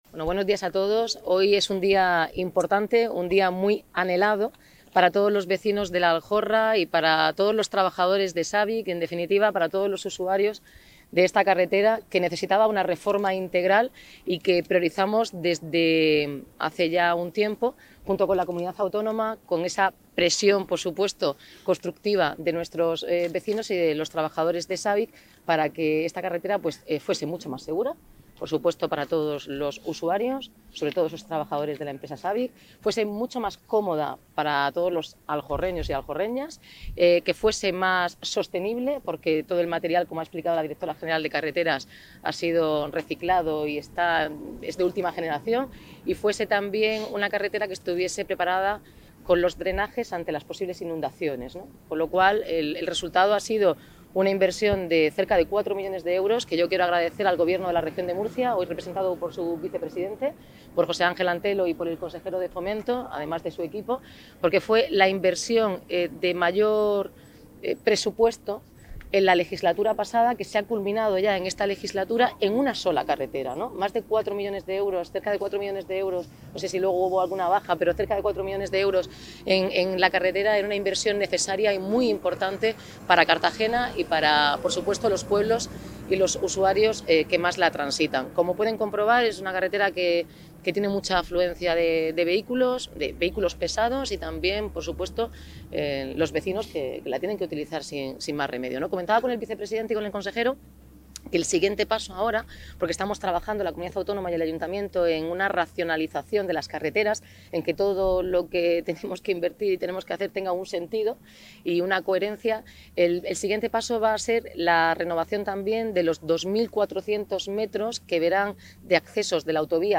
Enlace a Declaraciones inauguración de la nueva carretera RM-602 de La Aljorra.